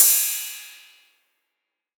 808CY_6_TapeSat_ST.wav